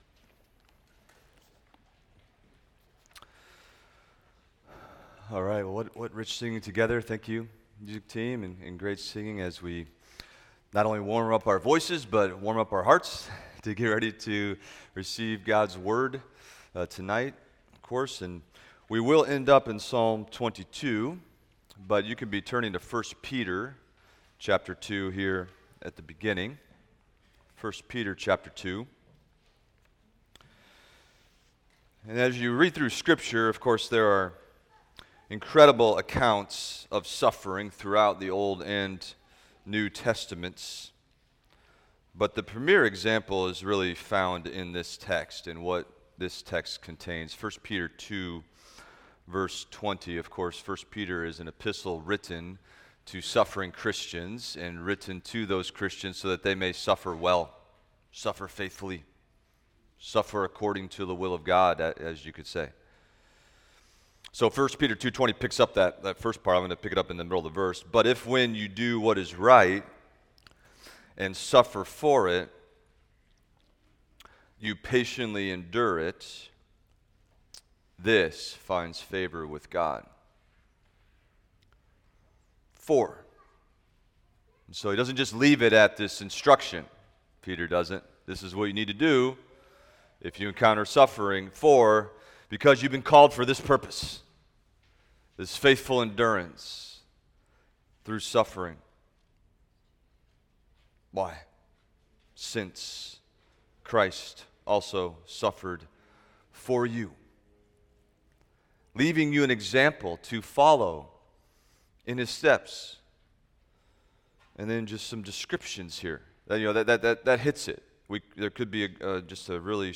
Expository Preaching from the Psalms – Psalm 119:97-104 - Treasuring the Work of the Word
Psalm 119:97-104 Sermon Outline